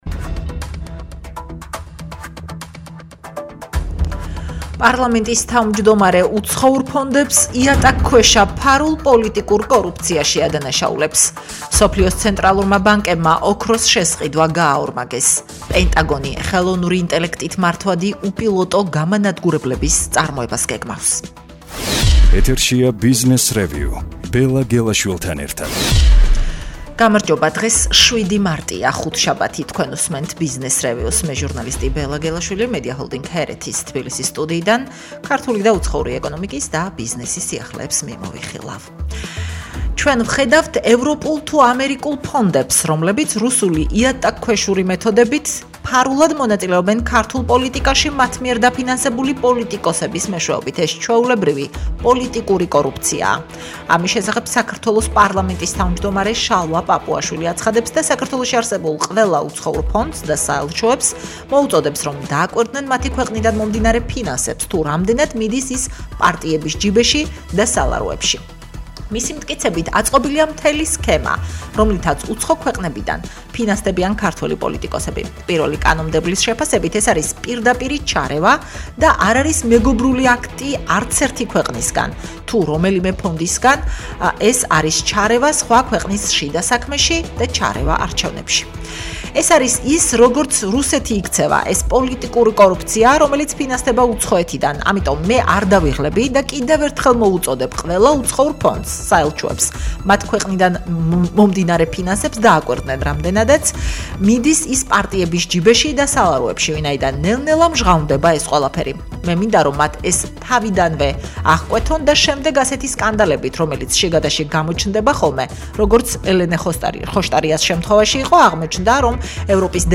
„ბიზნესრევიუ” [გადაცემა] – 07.03.2024.